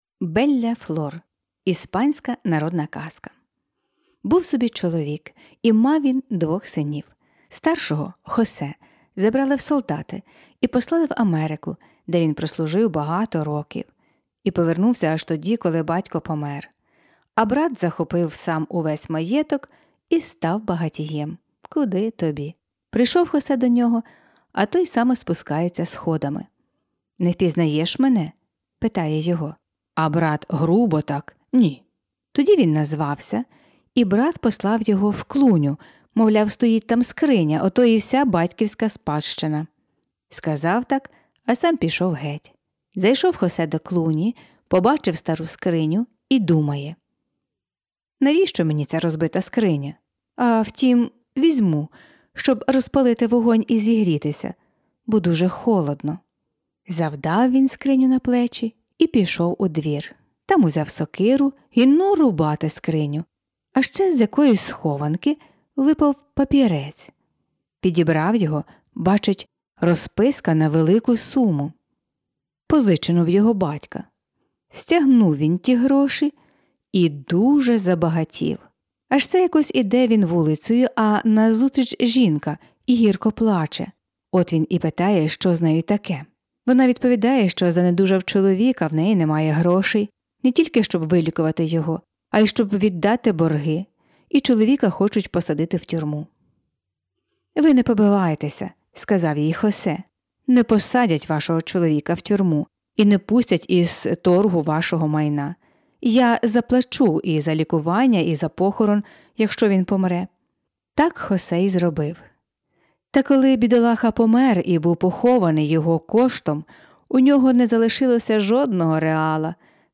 Жанр: Казка на добраніч Автор